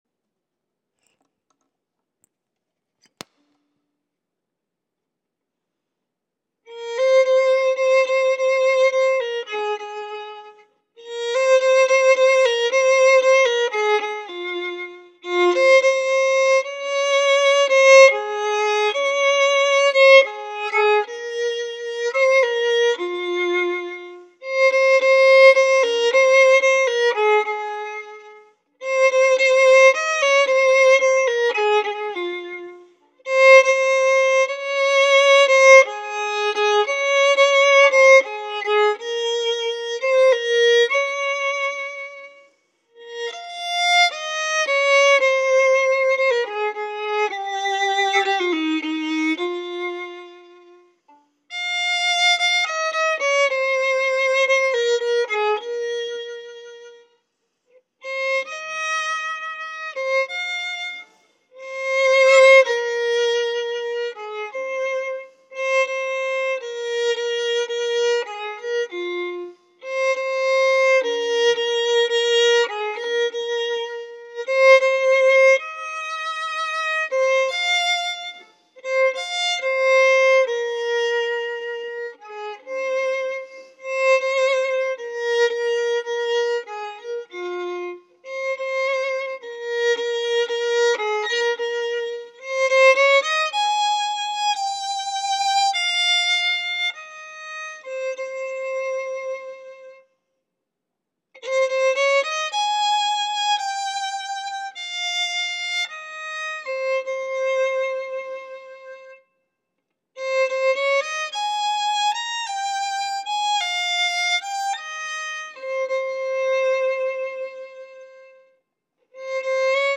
These are a few rough recorded samples